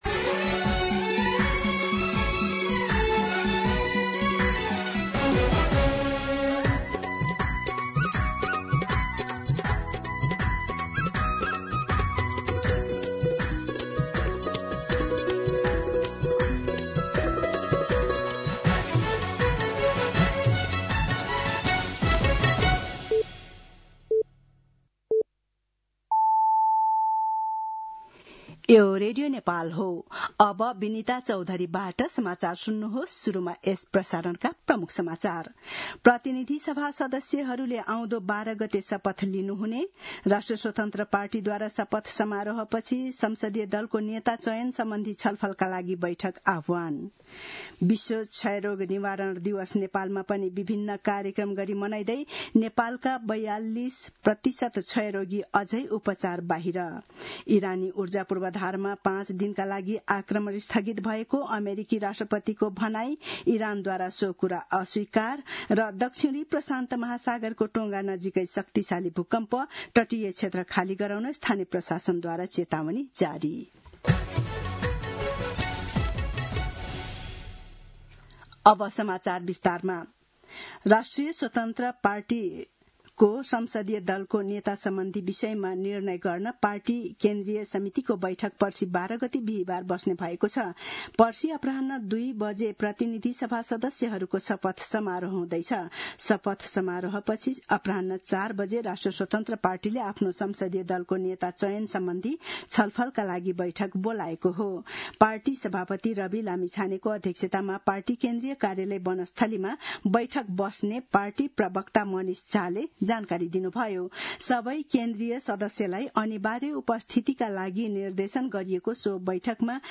दिउँसो ३ बजेको नेपाली समाचार : १० चैत , २०८२
3pm-News-10.mp3